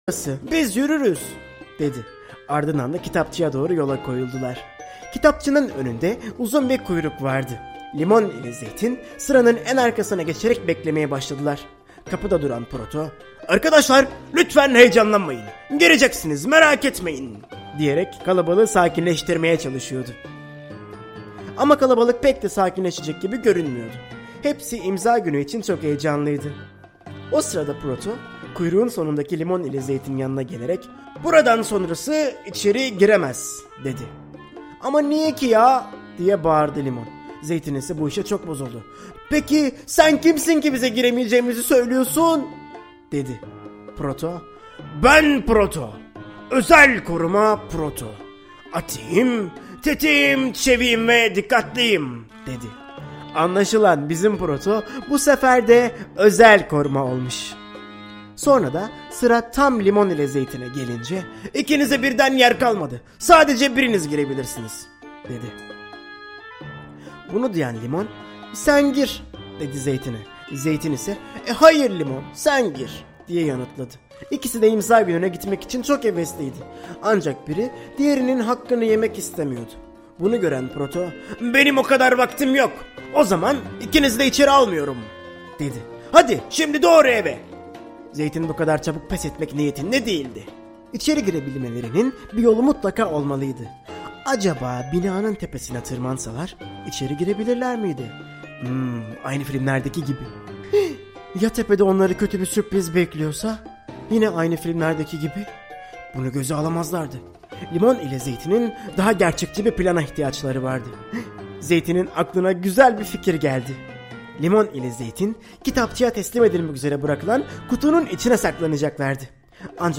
Limon ile Zeytin - Arka Kapı - Seslenen Kitap